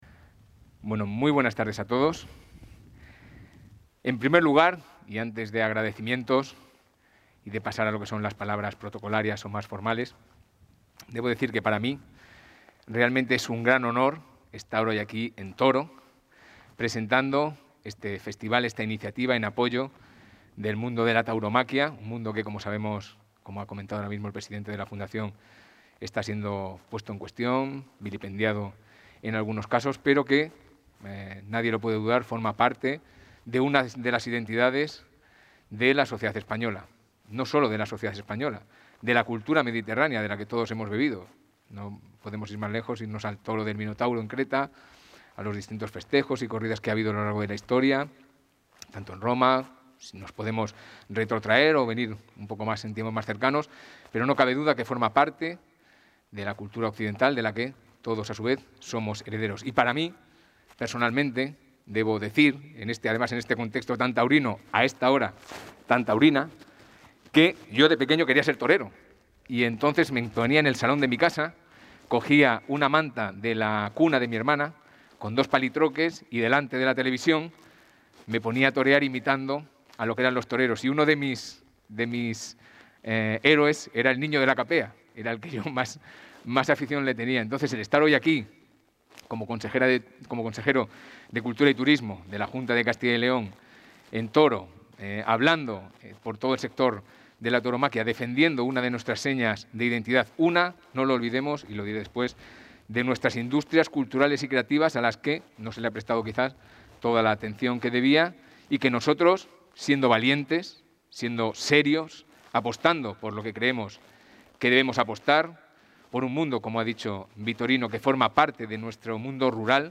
Intervención del consejero de Cultura y Turismo.